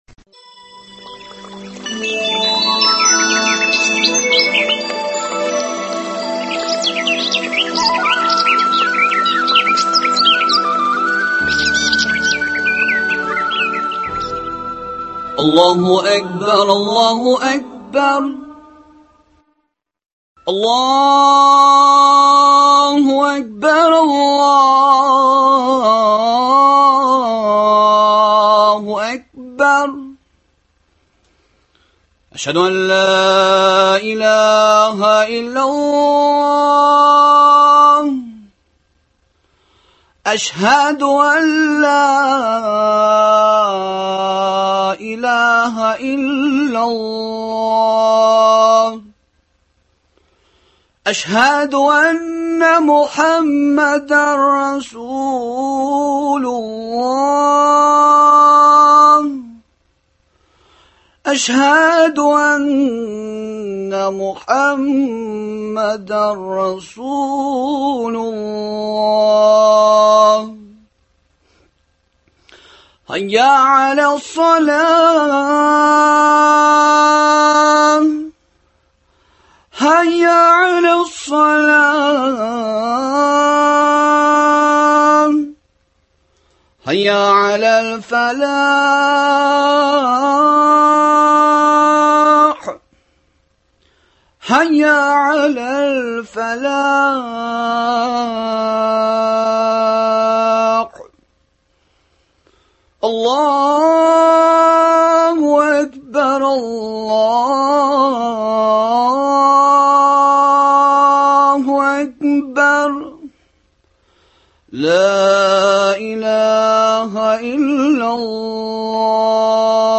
Ир-атларның бәйрәме – бәйрәм генәме, әллә инде зур җаваплылык турында искә төшерүме? Болар турында студиядә кунагыбыз